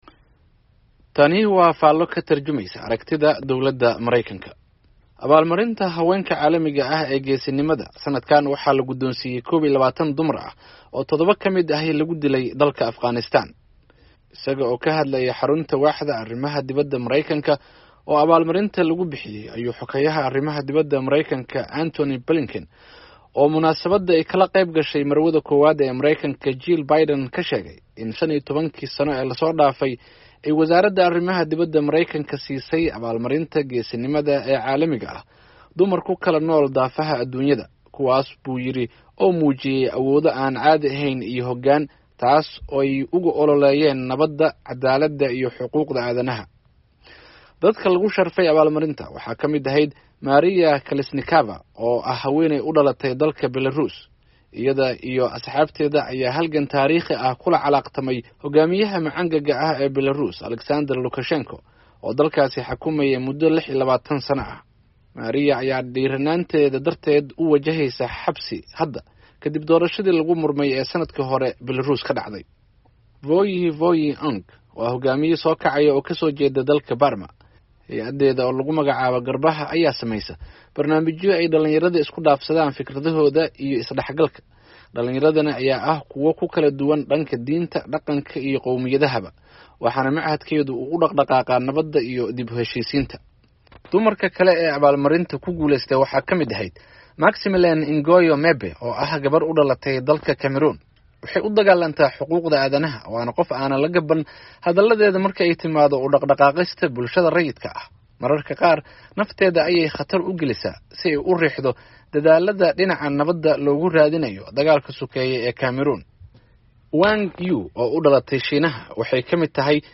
Tani waa faallo ka tarjumeysa aragtida dowladda Mareykanka.